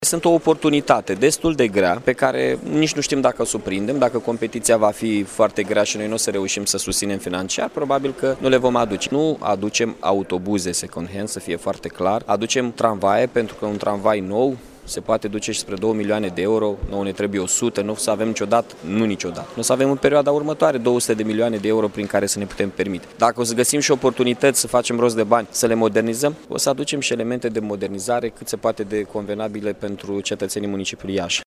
Primarul Iaşului, Mihai Chirica a precizat că în momentul de faţă un tramvai nou costă aproximativ două milioane de euro şi necesarul Iaşului ar fi de o sută de tramvaie: